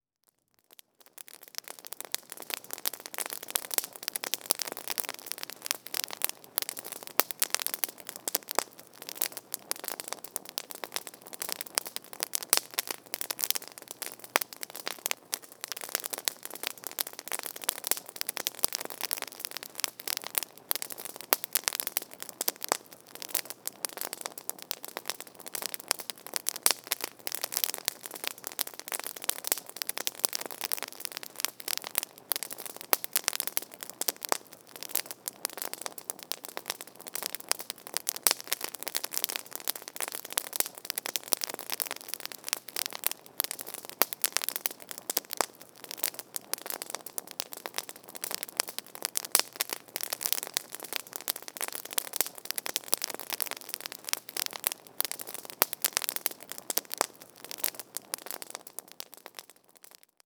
Fire-Campfire-Small-Campfire-Heavy-Crackling.wav